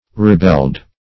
(r[-e]*b[e^]ld); p. pr. & vb. n. Rebelling.] [F. rebeller,